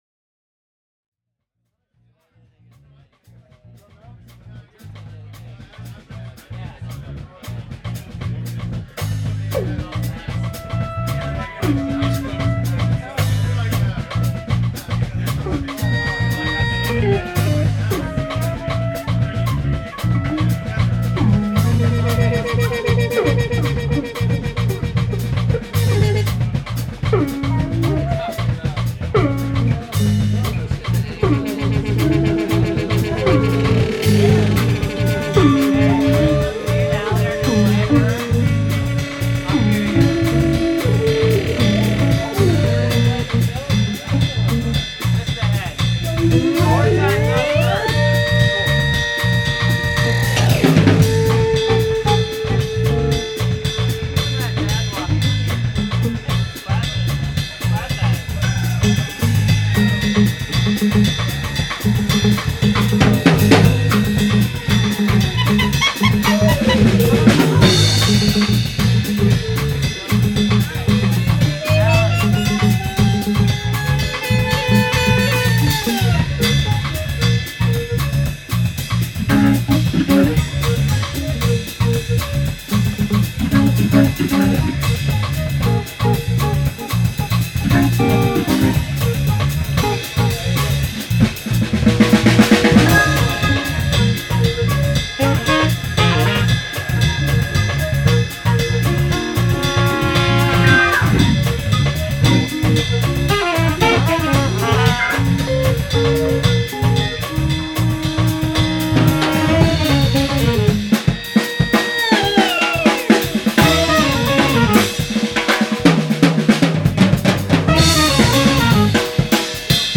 Performed at Jazzbones in Tacoma, 12.12.9